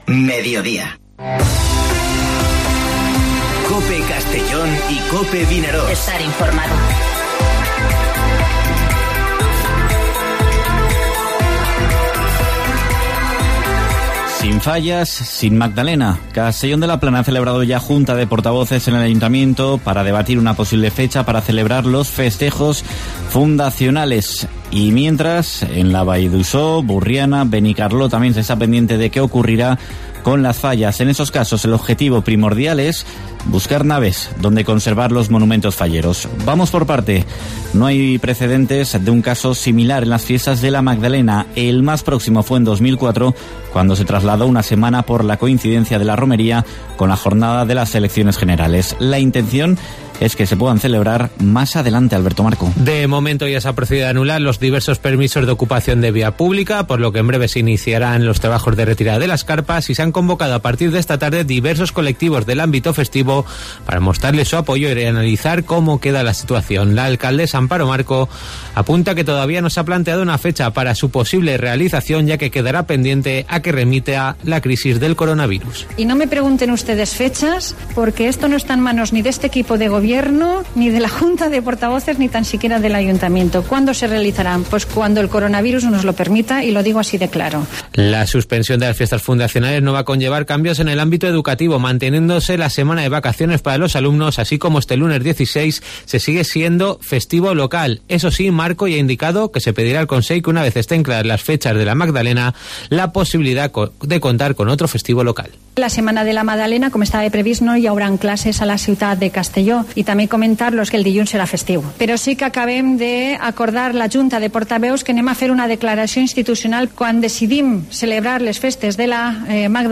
Informativo Mediodía COPE en la provincia de Castellón (11/03/2020)